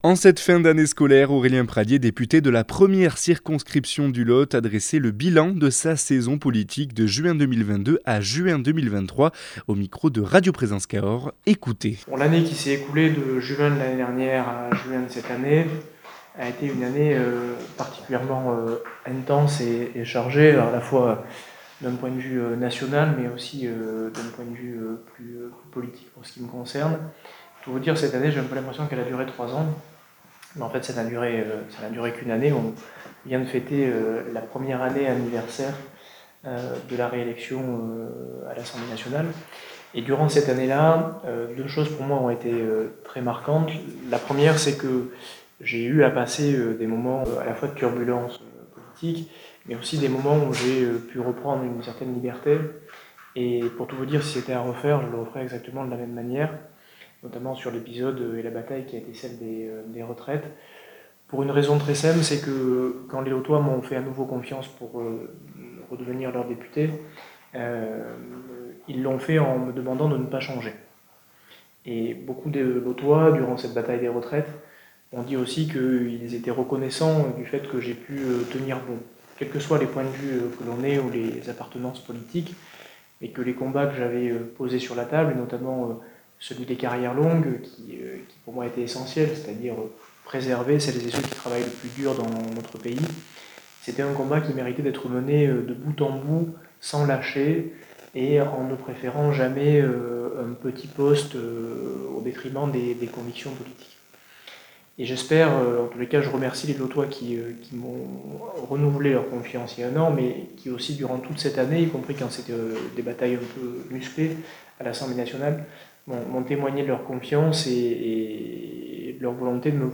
Aurélien Pradier député de la première circonscription du Lot aborde au micro de radio présence Cahors son bilan de juin 2022 à juin 2023 !